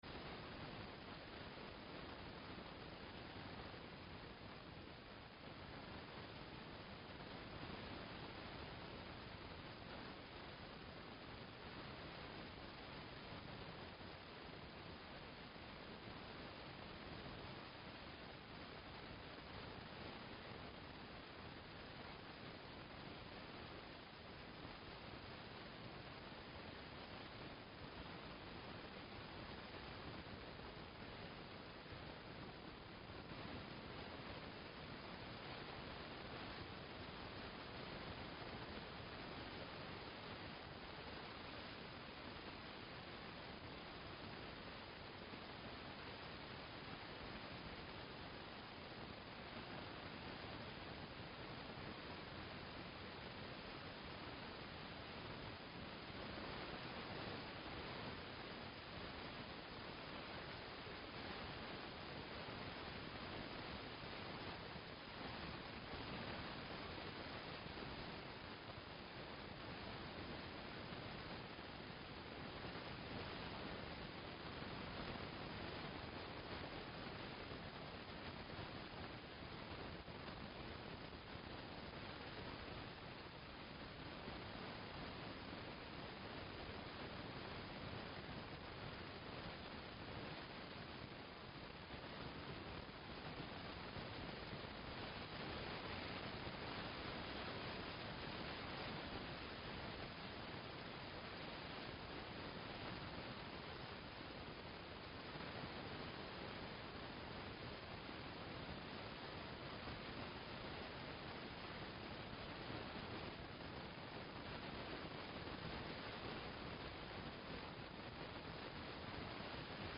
Sermon9_24_17.mp3